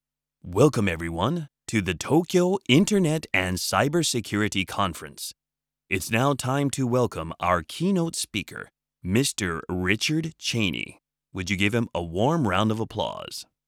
バイリンガル司会・MC
ボイスサンプル
ショー、レセプション　MC
カンファレンス　MC